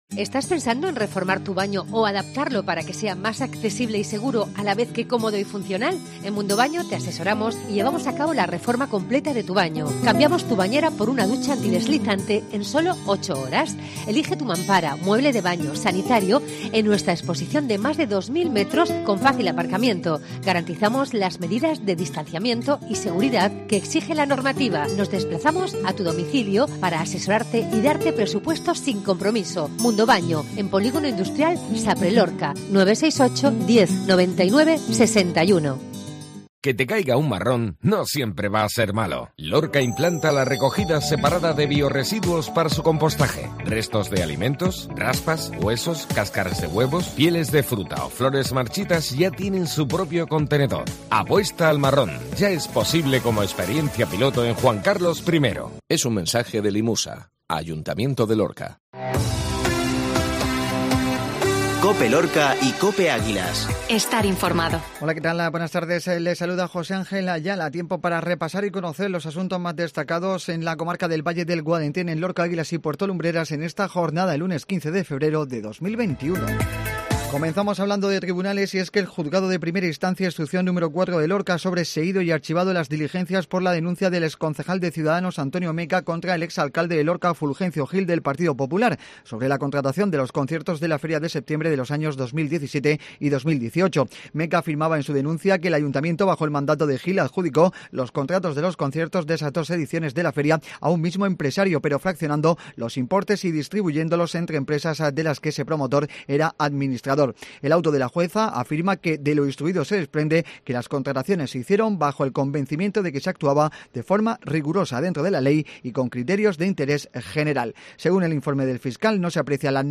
INFORMATIVO MEDIODÍA COPE LUNES